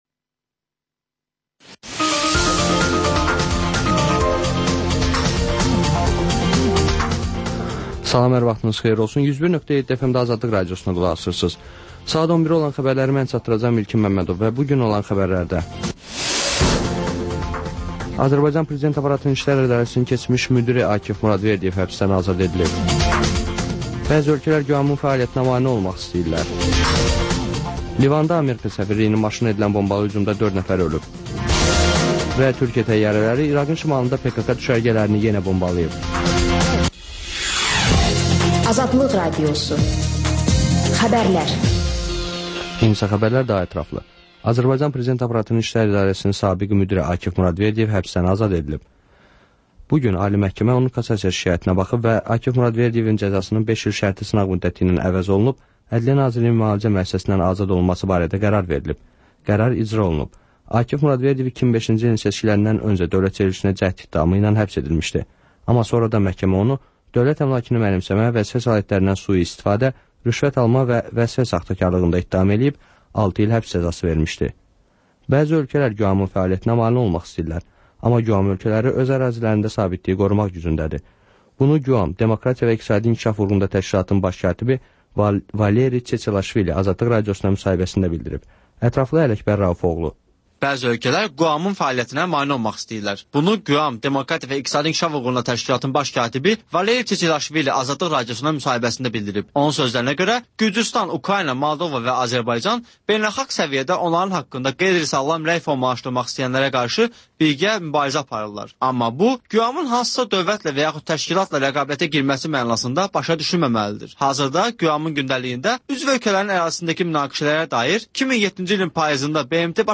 Xəbərlər, müsahibələr, hadisələrin müzakirəsi, təhlillər, sonda ŞƏFFAFLIQ: Korrupsiya haqqında xüsusi veriliş